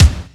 feverkick.wav